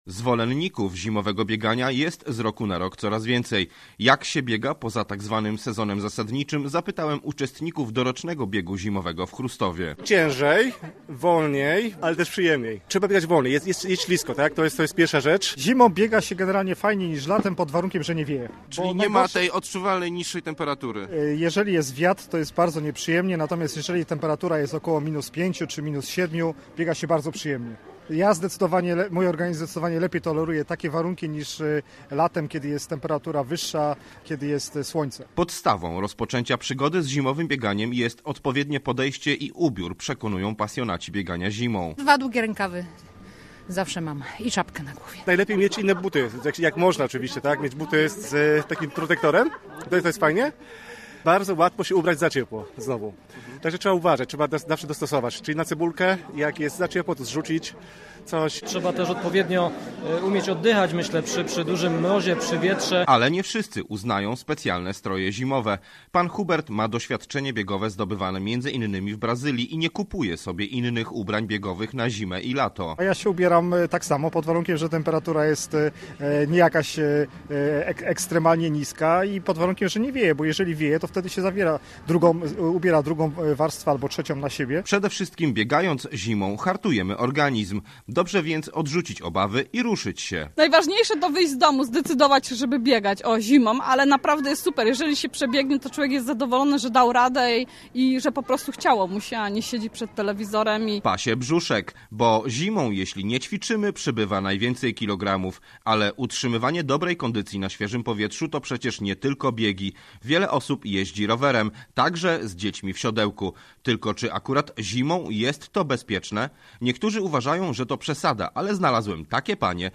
O wyższości zimowego biegania nad letnim naszego reportera postanowili przekonać uczestnicy zawodów, jakie rozegrano w miniony weekend.